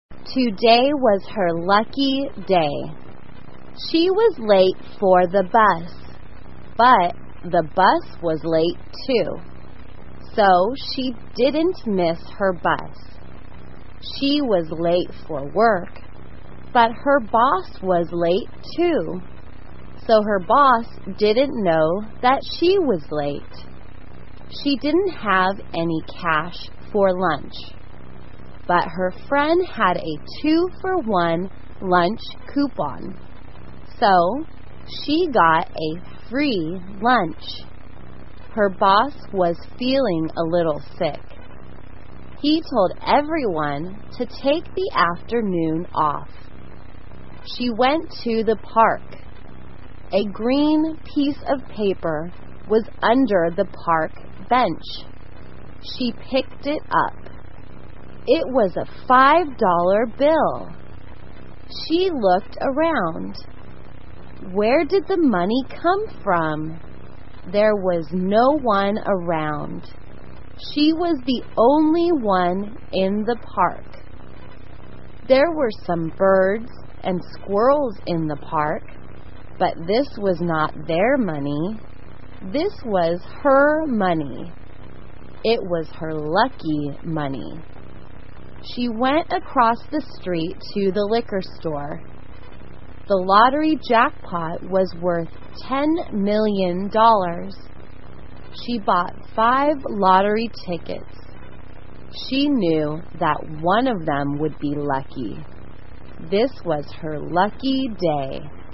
慢速英语短文听力 幸运日 听力文件下载—在线英语听力室